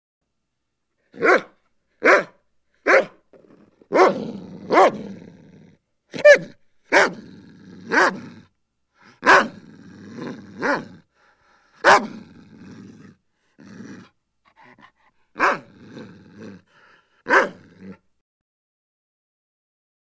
Dog, German Shepherd Snarls And Growls With One High Pitched Yelp. Close Perspective.